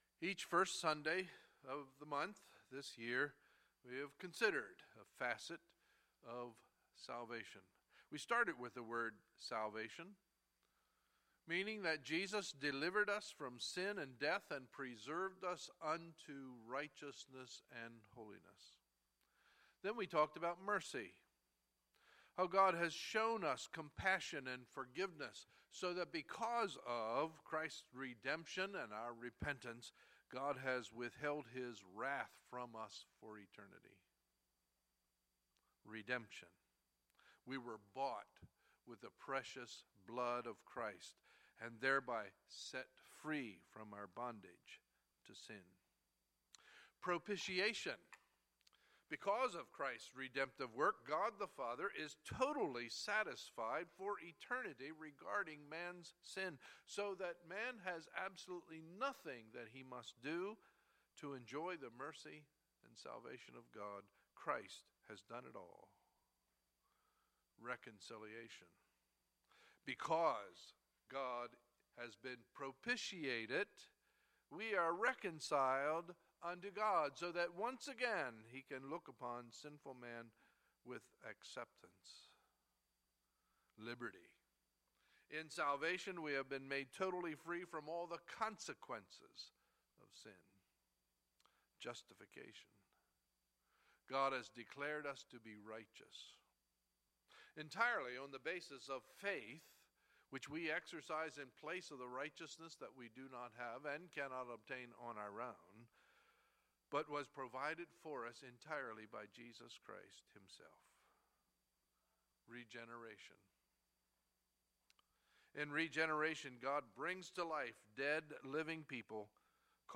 Sunday, November 6, 2016 – Sunday Morning Service